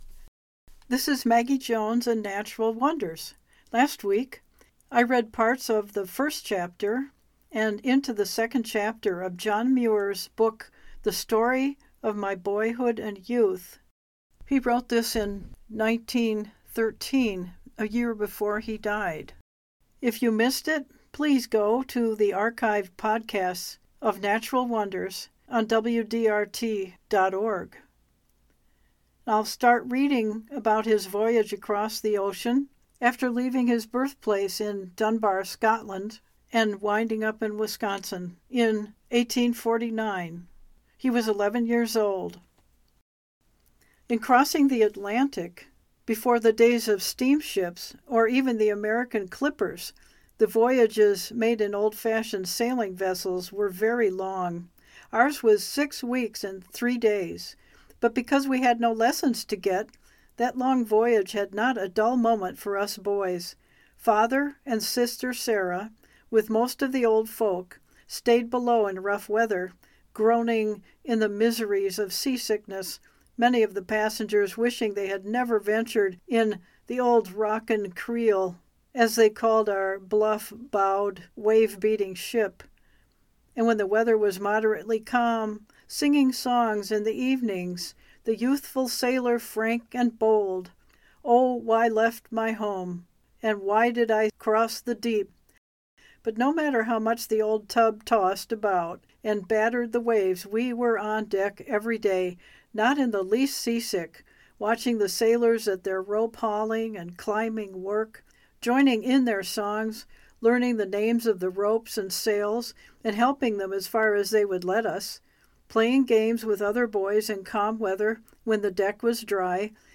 Today the second reading from John Muir’s book The Story of My Boyhood and Youth, written one year before his death in 1914. This gives us delightful insight into life more than 150 years ago traveling to America from Scotland on a rolling “bluff bowed boat” and discovering, in Wisconsin, new species of birds, animals and plants […]